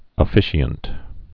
(ə-fĭshē-ənt)